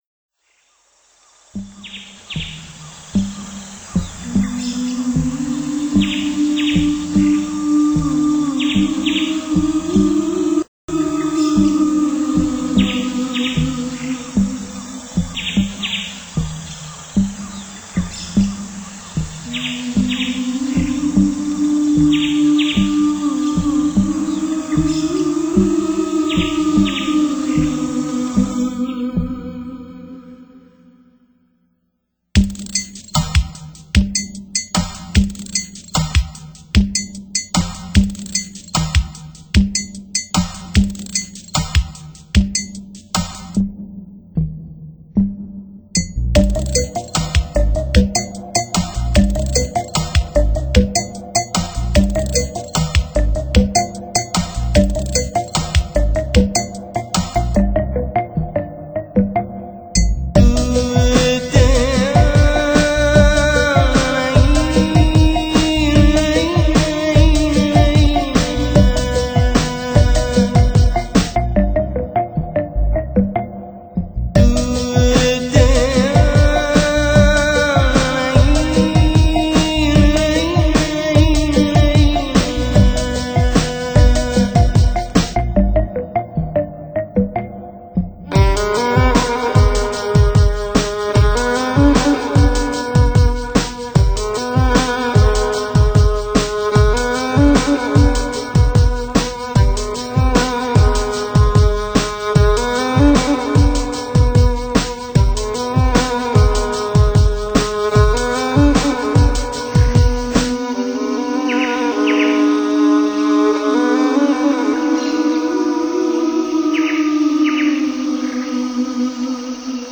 专辑语言：纯音乐